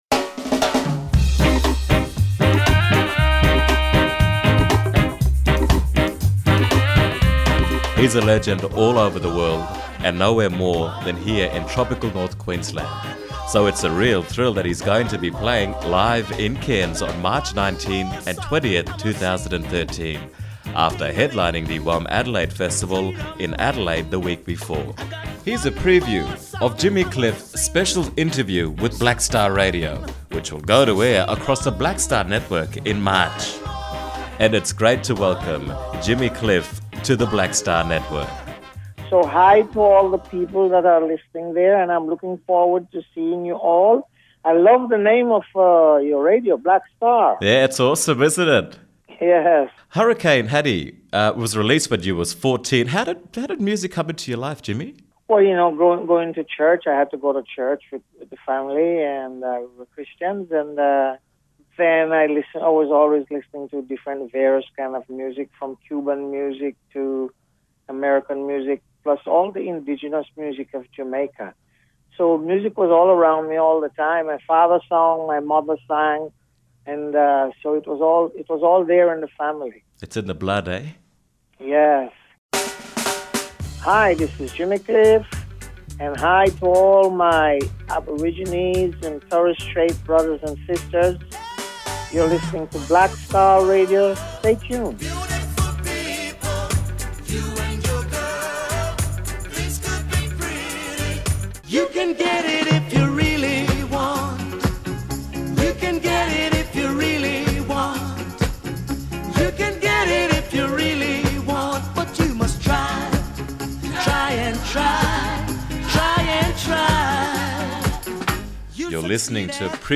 Jimmy Cliff Preview Interview
jimmy-cliff-pre-interview.mp3